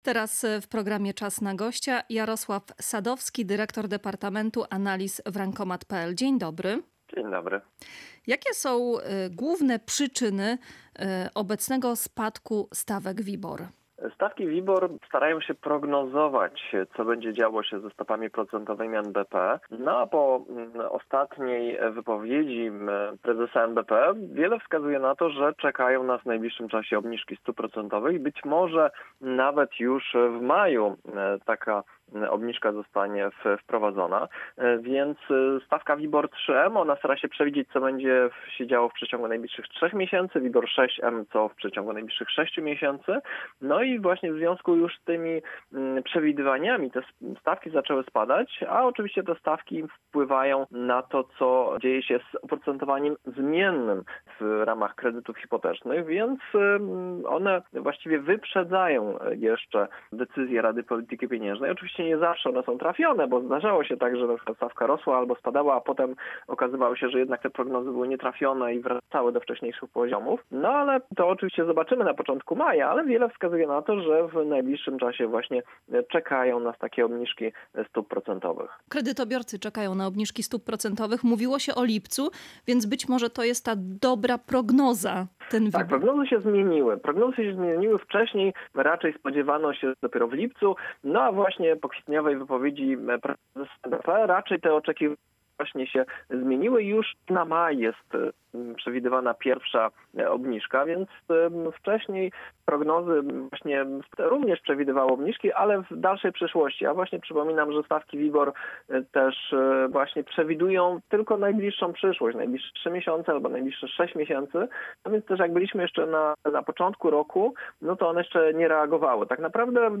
Kredytobiorcy odetchną z ulgą: być może w maju raty spadną nawet o 100 zł • Teraz Gospodarka • Polskie Radio Rzeszów